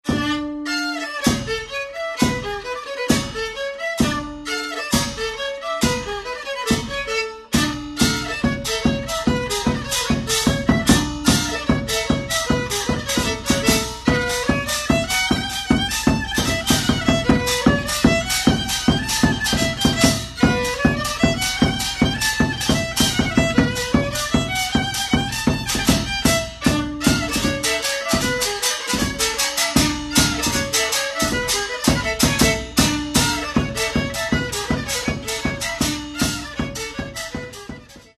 Троїсті музики.